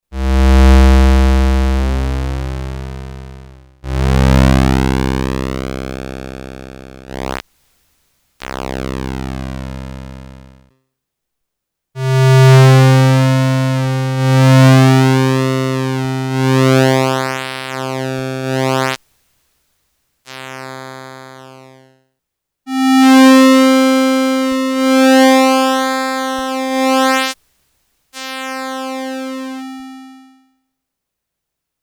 Synthesizer (2013)
pulse width change